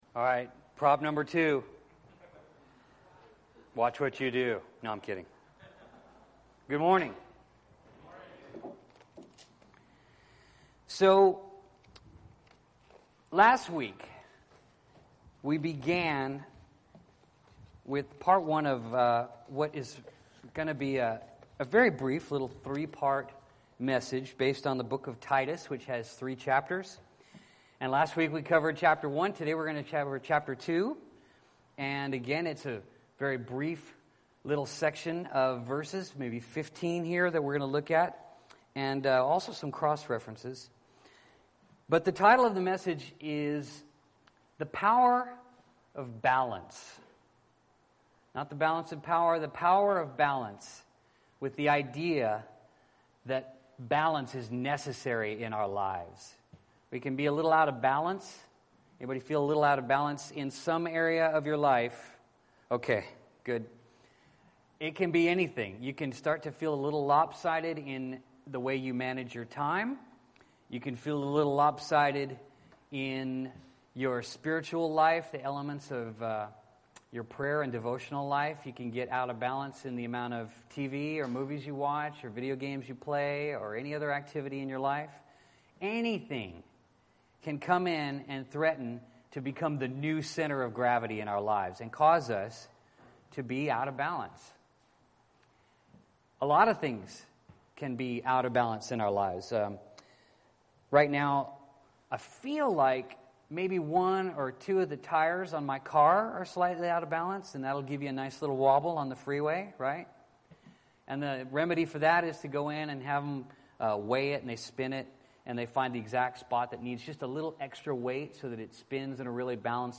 Bible Text: Titus 2 | Preacher